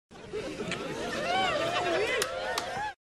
Play, download and share naughty oooh original sound button!!!!
naughty-oooh.mp3